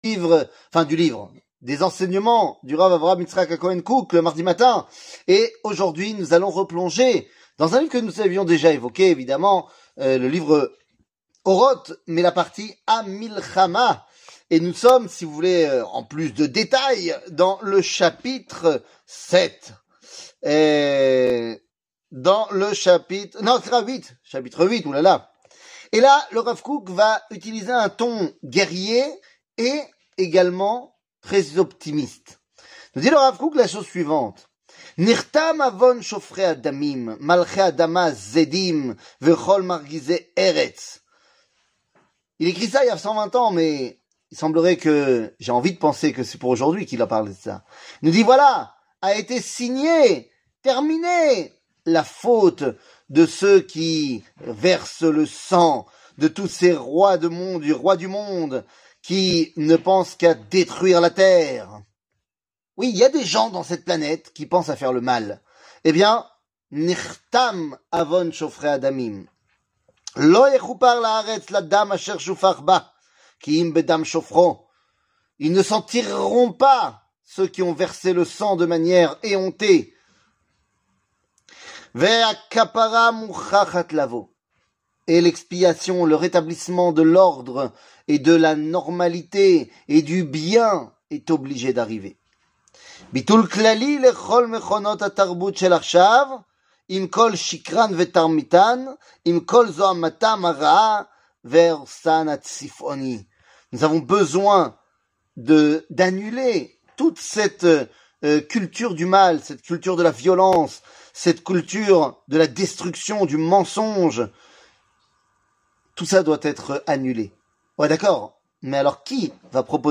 Rav Kook, Orot Amilhama, La lumière d'Israel 00:04:23 Rav Kook, Orot Amilhama, La lumière d'Israel שיעור מ 09 ינואר 2024 04MIN הורדה בקובץ אודיו MP3 (4.01 Mo) הורדה בקובץ וידאו MP4 (5.72 Mo) TAGS : שיעורים קצרים